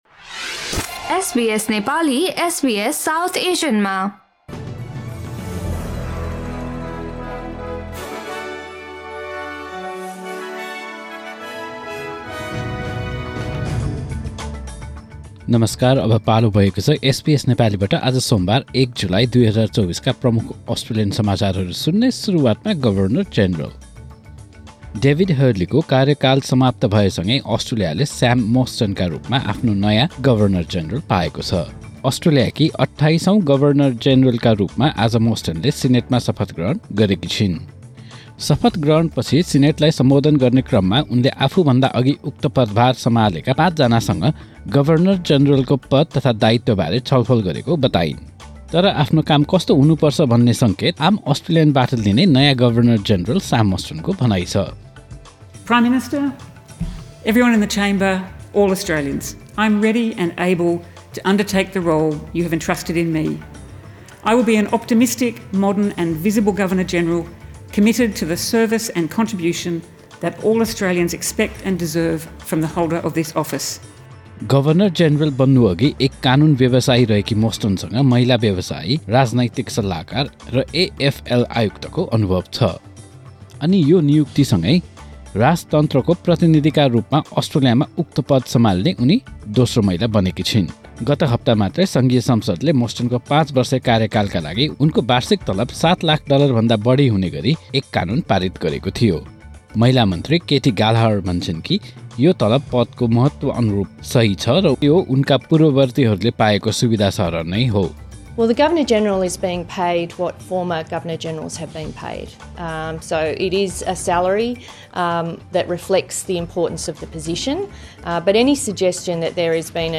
Listen to the latest bitesize top news from Australia in Nepali.